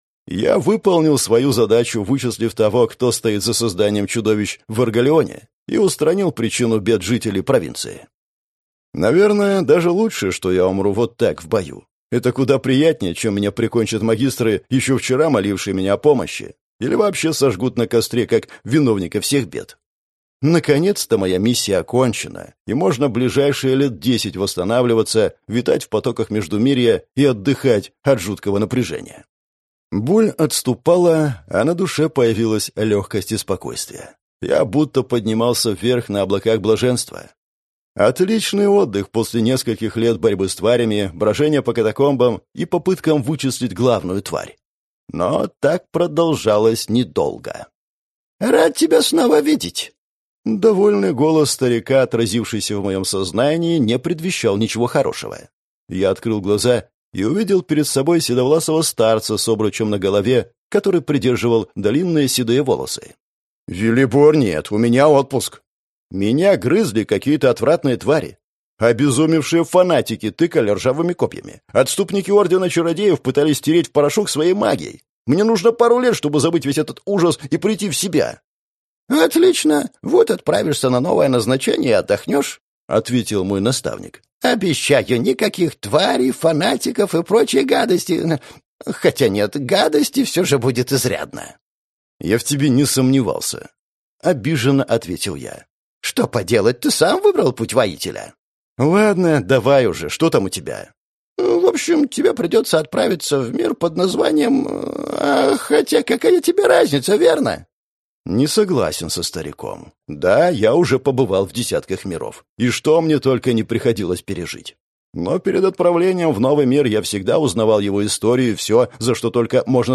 Аудиокнига Михаил Гущин. Книга 1. Ростовская академия | Библиотека аудиокниг